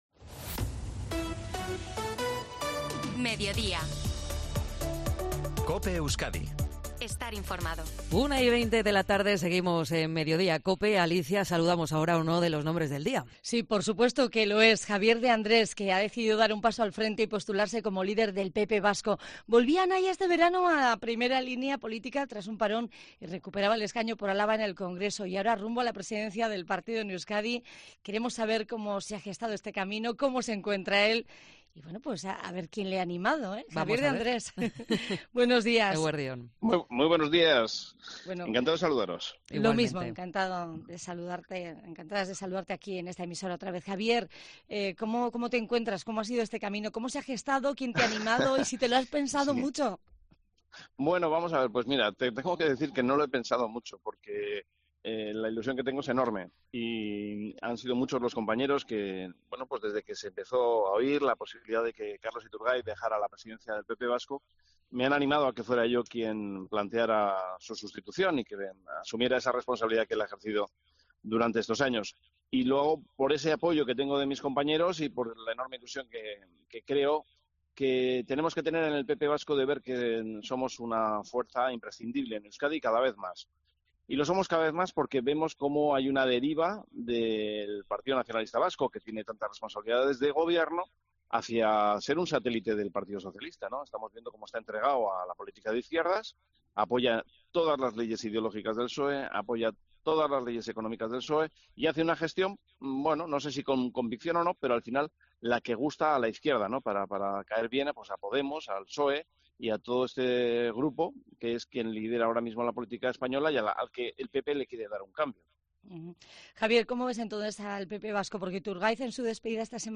Entrevista a Javier de Andrés, en COPE Euskadi